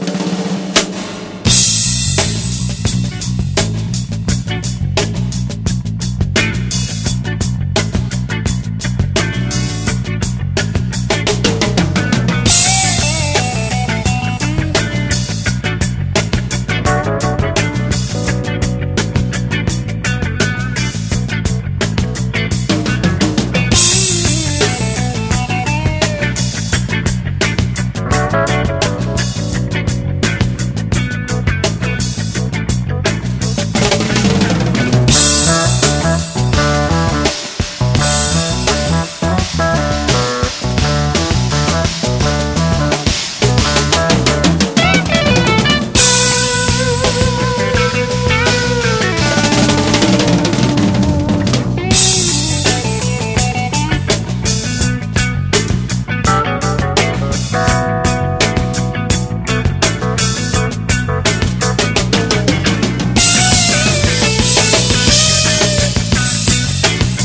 DJユースなヨーロピアン・レアグルーヴ/自主系ジャズを集めたナイス・コンピ！